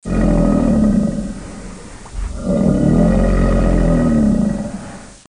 American Alligator